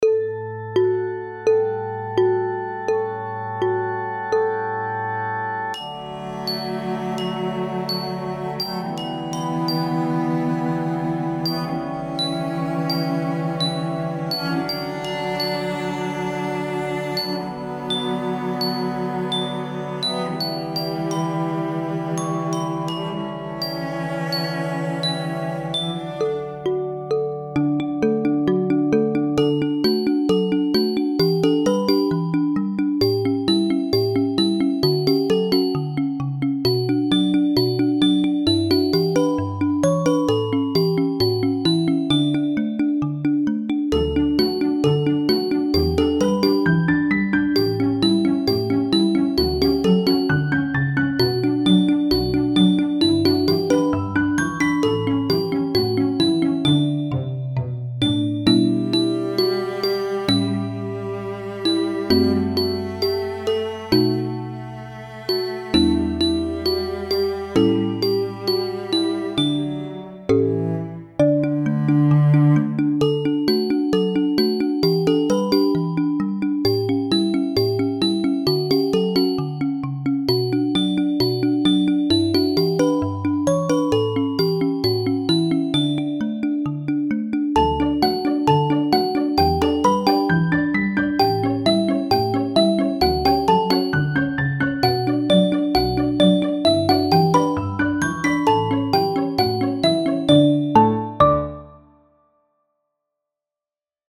Epoque :  Musique d'aujourd'hui
Genre :  ChansonComptine
Enregistrement instrumental